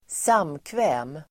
Ladda ner uttalet
samkväm substantiv, social [gathering] Uttal: [²s'am:kvä:m] Böjningar: samkvämet, samkväm, samkvämen Synonymer: gille, samvaro, umgänge Definition: enklare fest social substantiv, tillställning , samkväm , bjudning